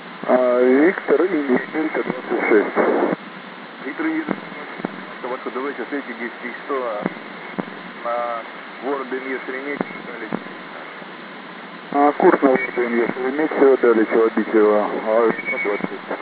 pomexa.wav